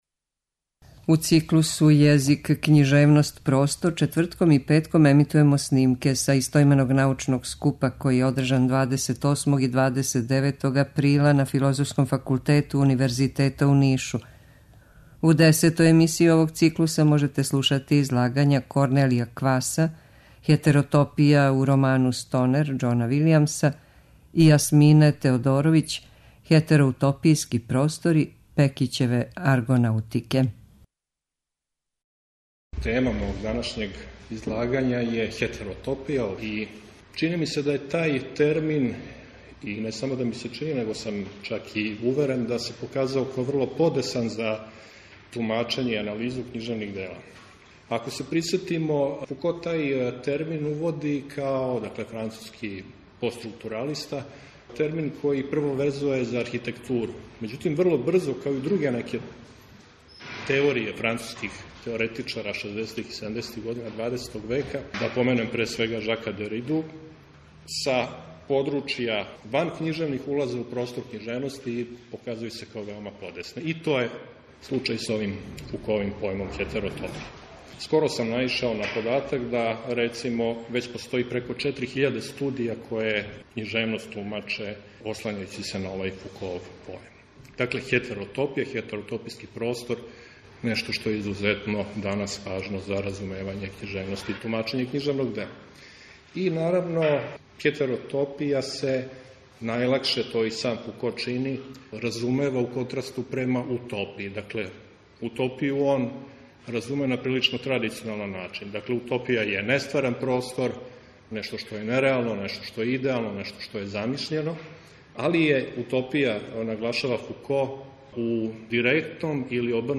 У циклусу ЈЕЗИК, КЊИЖЕВНОСТ, ПРОСТОР четвртком и петком ћемо емитовати снимке са истoименог научног скупа, који је одржан 28. и 29. априла на Филозофском факултету Универзитета у Нишу.